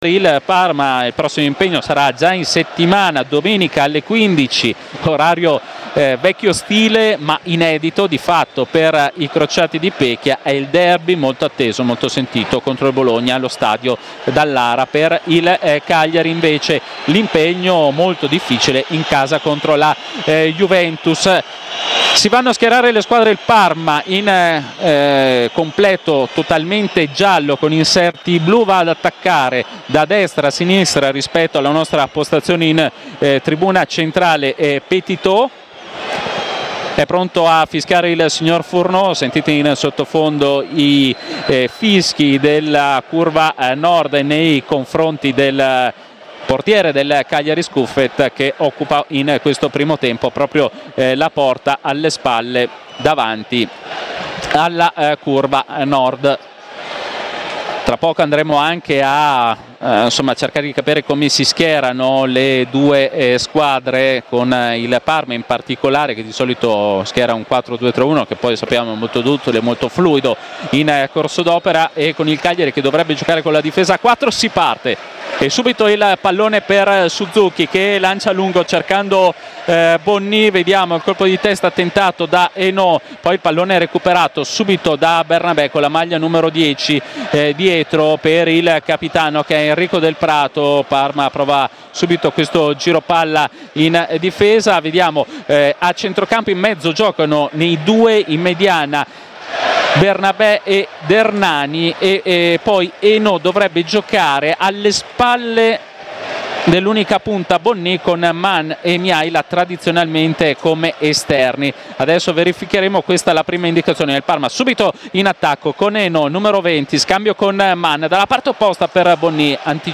Due cambi per Pecchia rispetto a Lecce, inserendo Valeri al posto di Coulibaly sulla fascia sinistra e Hainaut ia centrocampo per Sohm, recuperato in extremis, ma non in grado di partire titolare. Radiocronaca